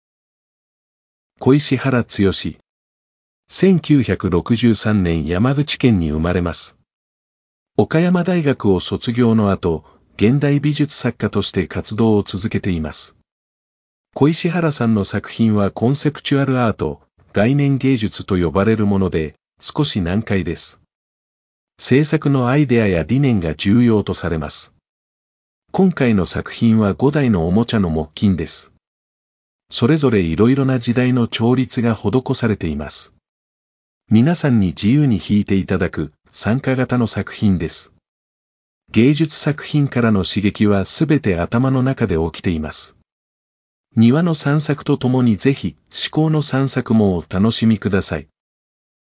日本語による解説